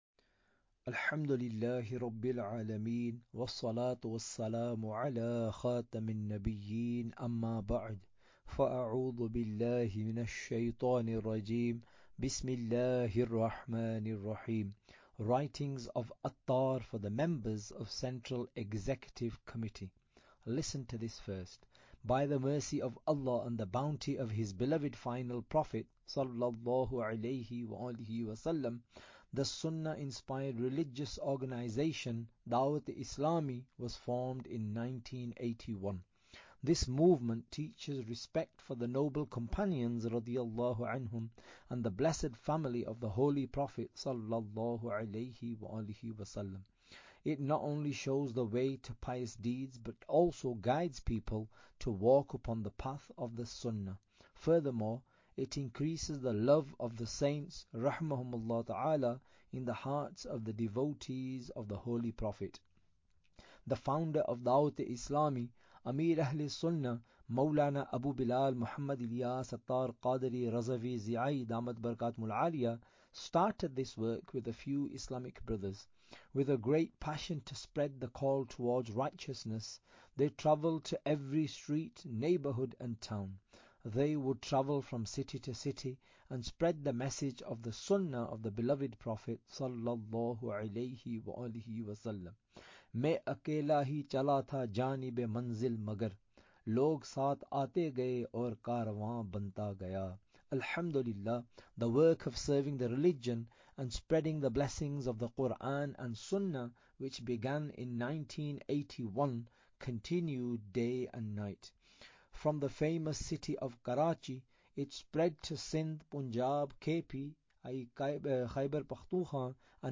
Audiobook - Writings Of Attar (English)